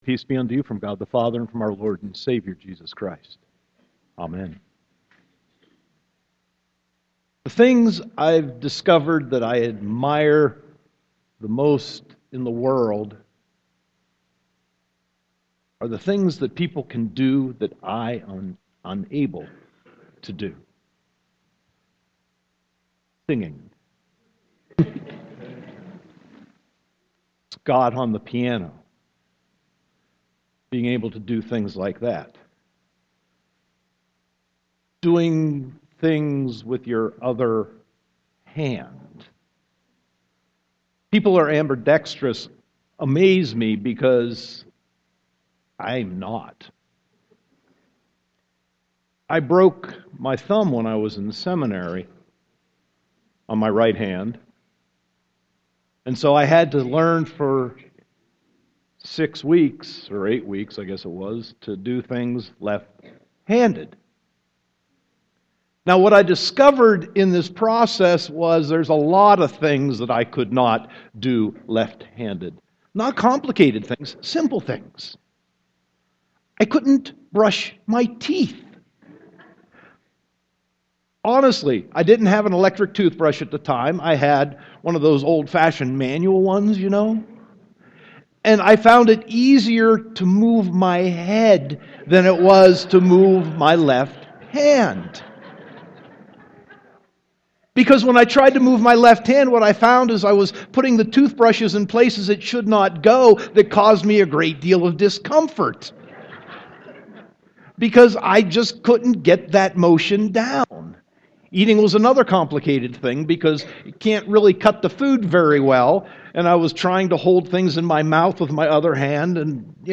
Sermon 3.8.2015